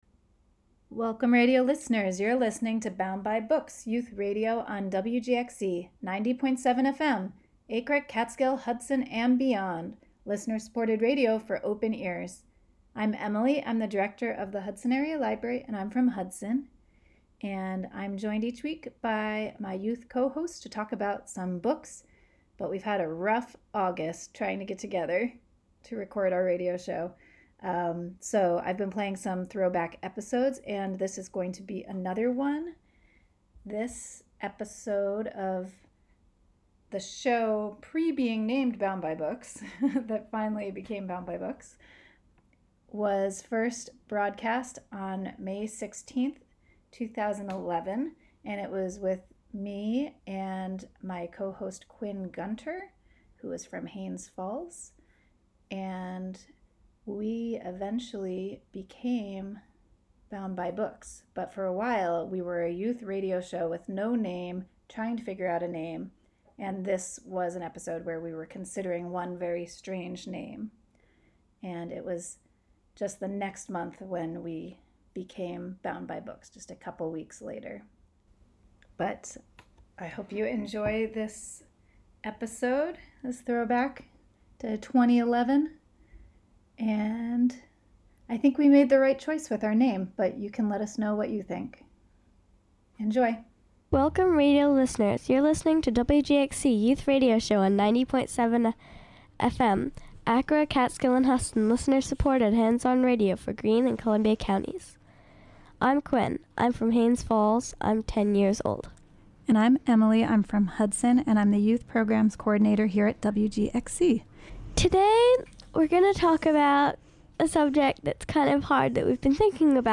The Bound By Books crew discusses books they love, books they're currently reading, and other teen media and literary topics.